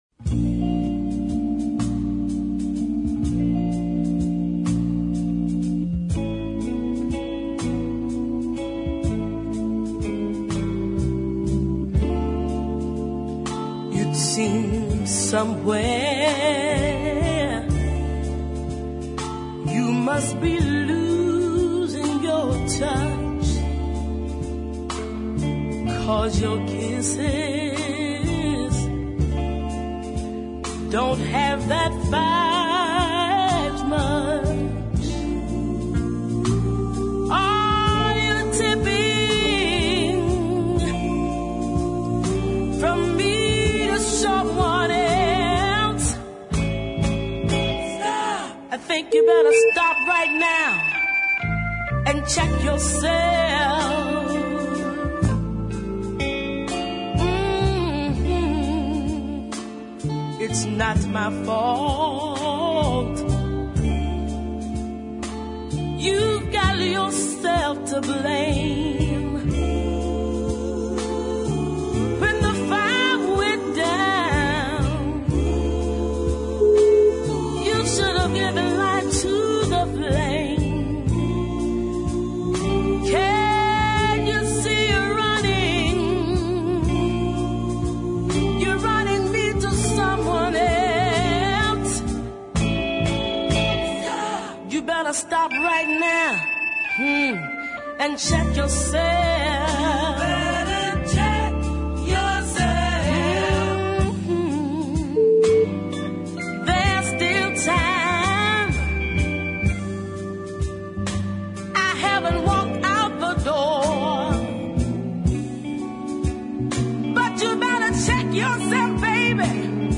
is a lovely blue tinged ballad